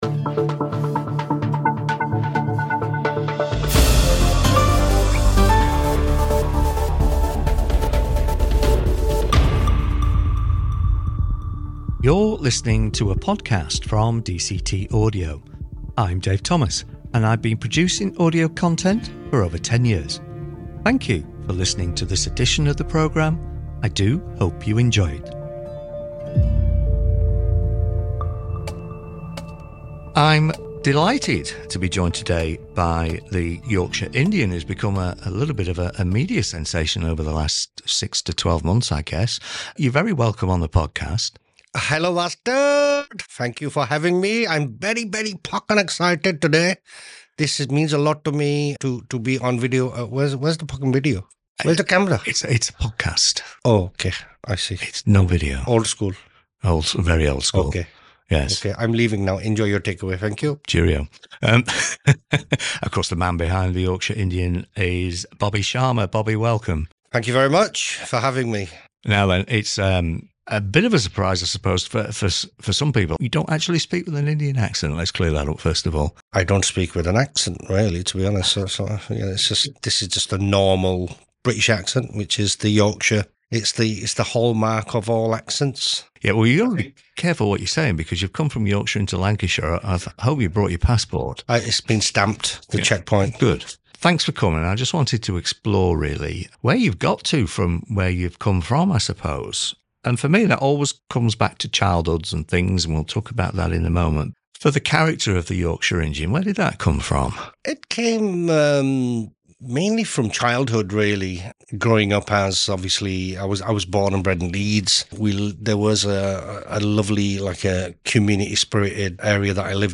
It’s a funny and yet touching conversation.